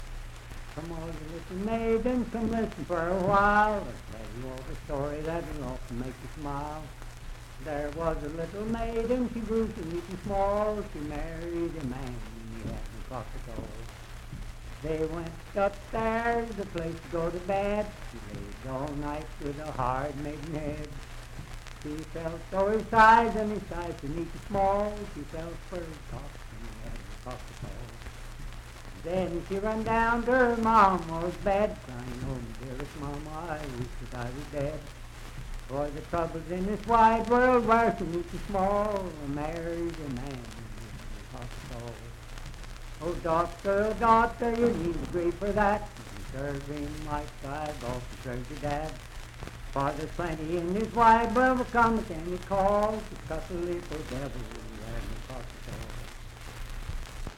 Unaccompanied vocal music and folktales
Bawdy Songs
Voice (sung)
Wood County (W. Va.), Parkersburg (W. Va.)